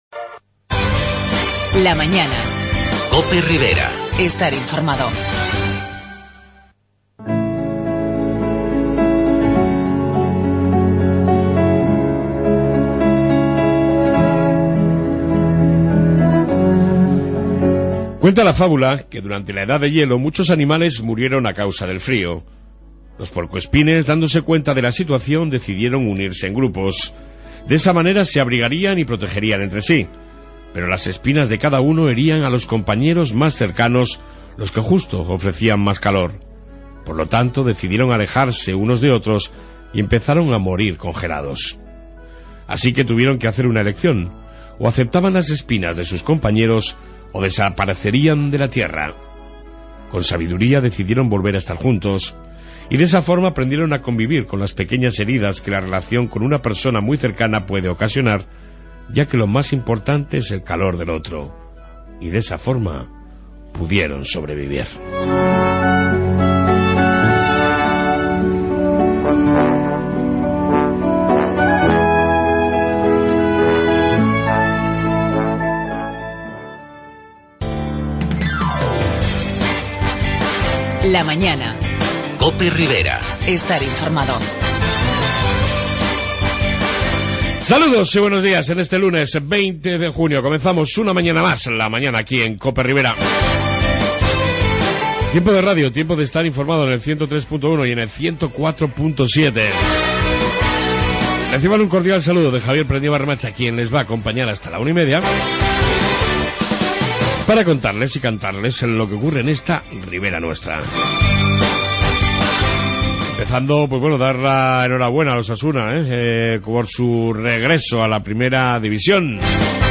AUDIO: Hoy os dejo con La reflexión diaria y la entrevista sobre la próxima apertura de Villa Javier